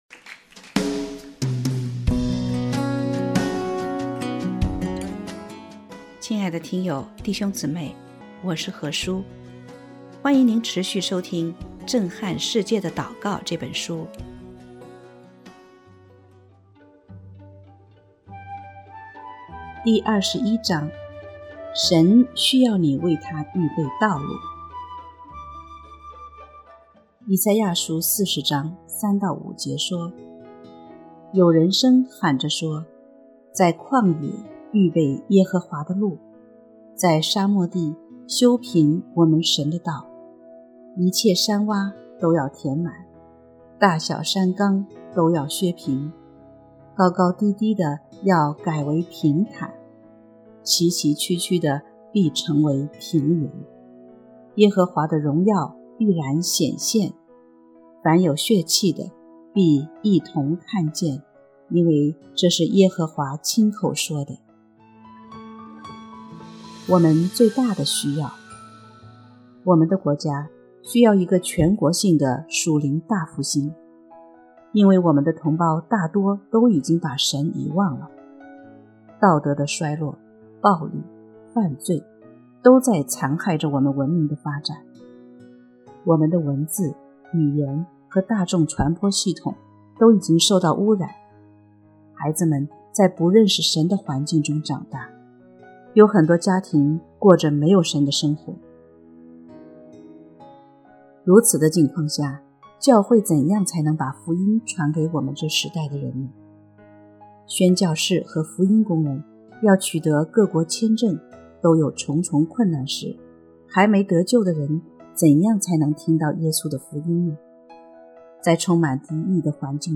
首页 > 有声书 | 灵性生活 | 震撼世界的祷告 > 震撼世界的祷告 第二十一章：神需要你为他预备道路